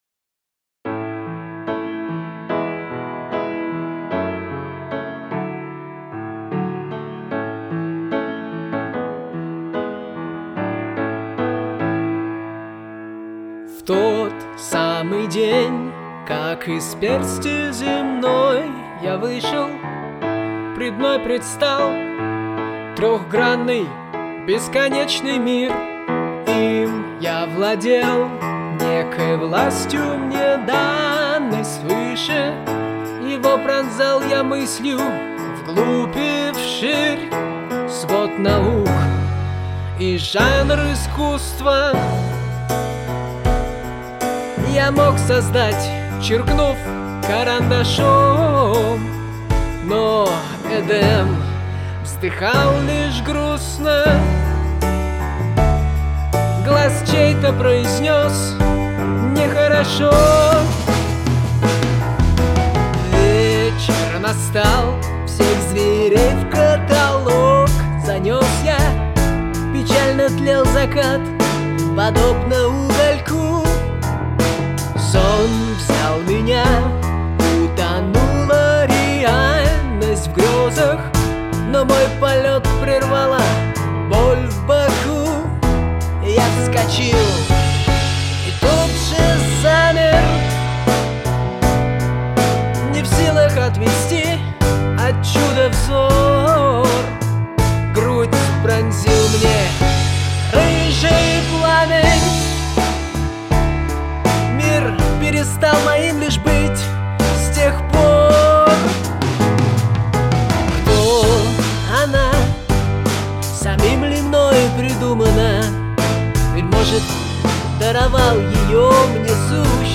"Песня Адама" (рок, баллада